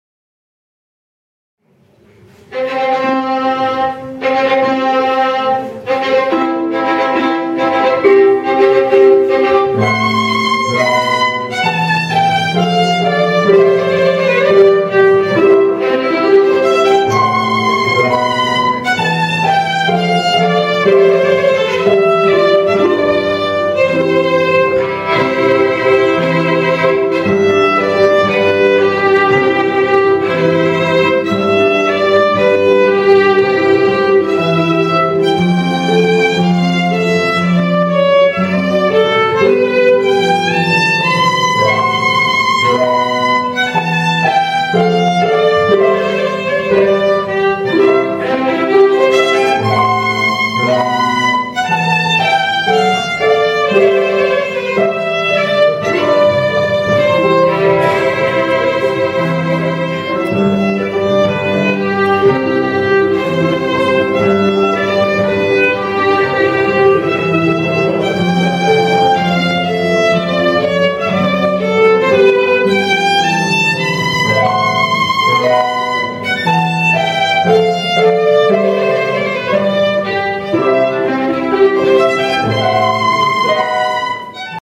archi